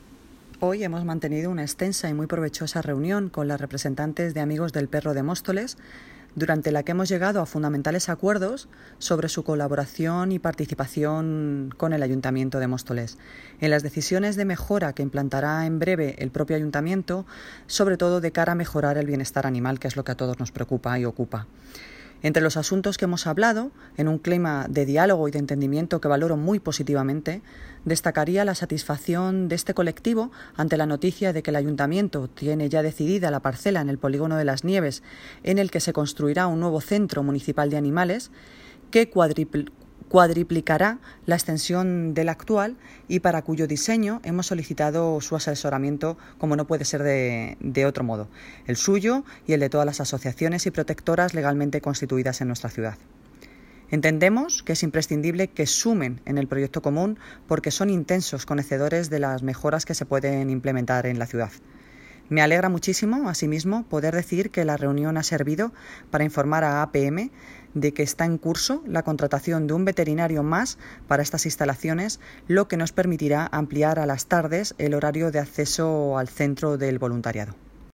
Audio - Noelia Posse (Alcaldesa de Móstoles) Sobre Centro Municipal de Acogida de Animales